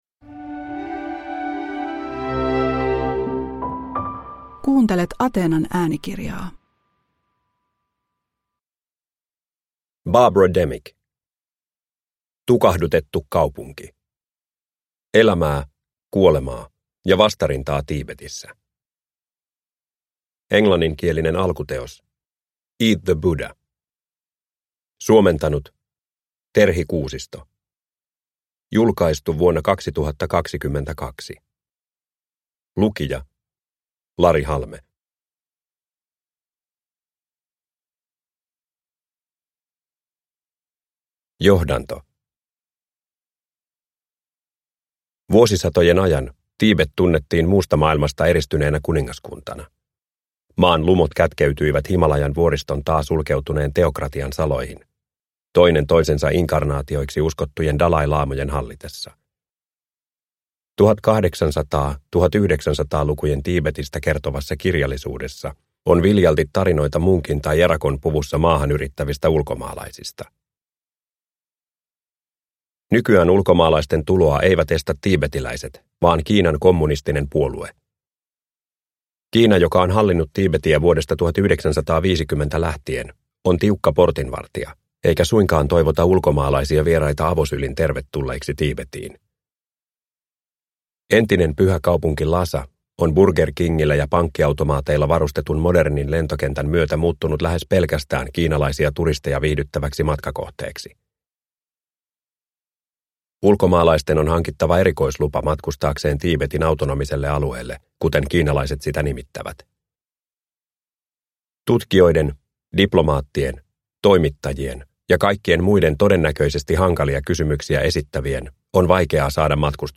Tukahdutettu kaupunki – Ljudbok – Laddas ner